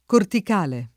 [ kortik # le ]